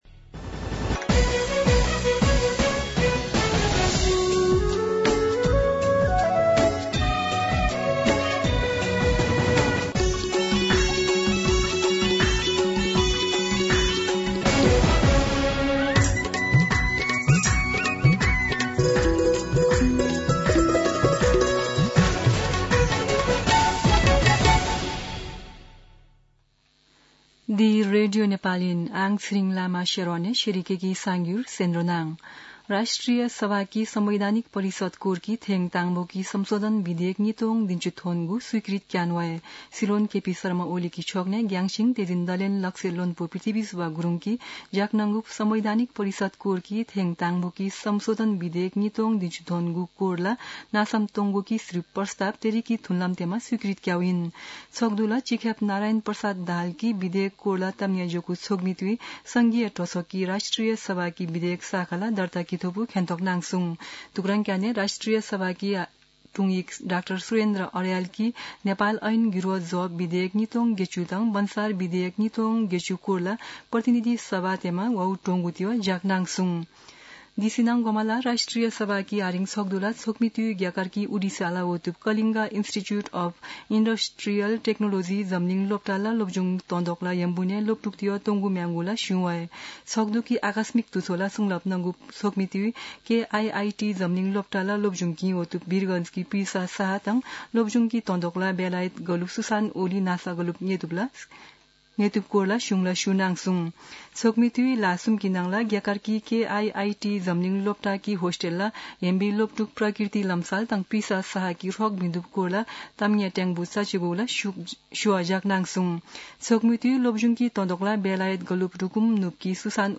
शेर्पा भाषाको समाचार : २१ वैशाख , २०८२
Sherpa-News.mp3